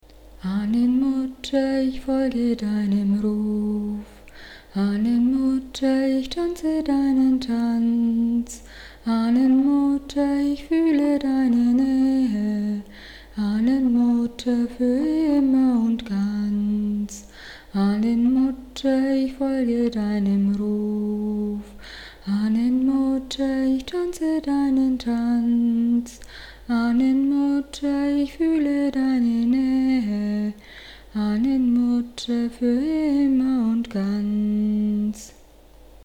Chants und Hexenlieder
Wichtig hierbei ist immer, dass wir tief und frei aus dem Körper heraus singen, ohne uns Gedanken darüber zu machen, ob wir nun einmal einen Ton treffen oder nicht.
Chants hingegen sind sehr kurze Stücke, die immer wieder wiederholt werden.